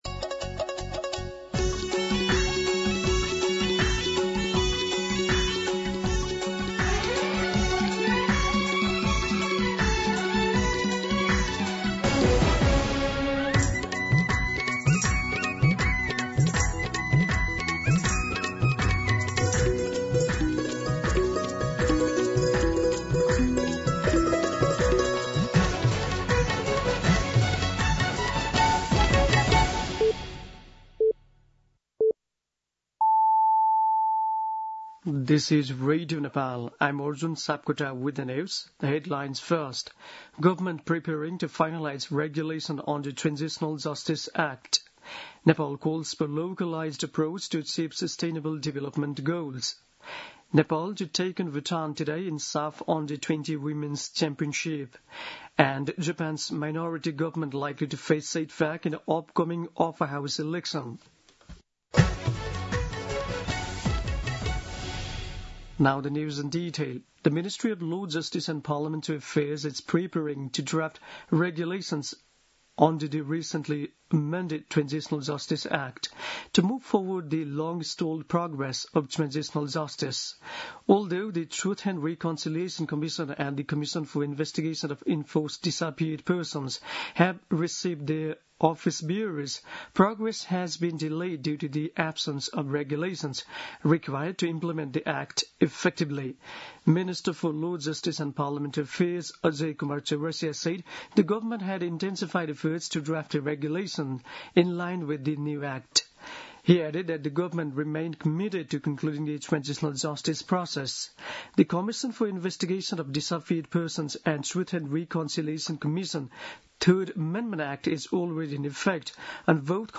दिउँसो २ बजेको अङ्ग्रेजी समाचार : १८ पुष , २०२६
2-pm-English-News-1.mp3